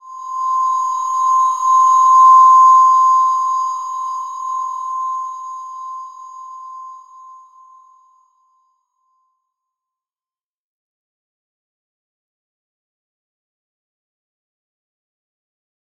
Slow-Distant-Chime-C6-f.wav